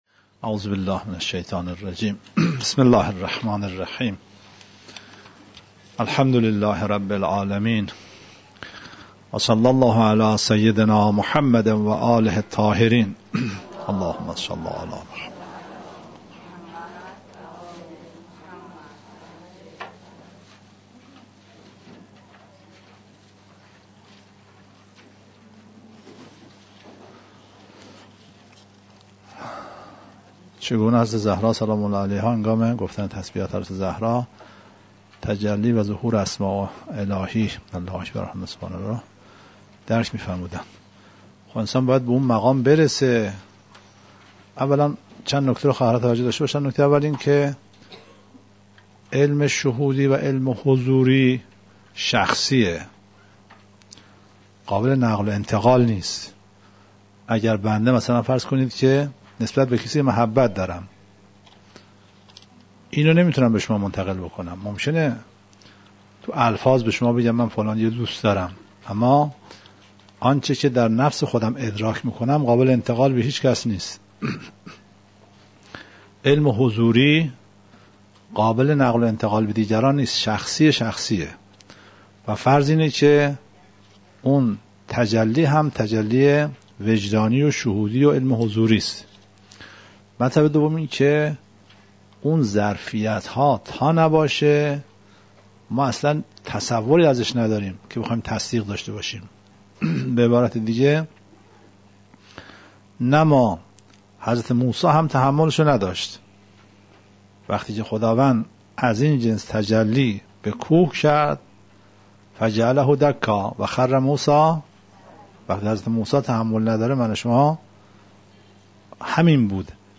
047 - تلاوت قرآن کریم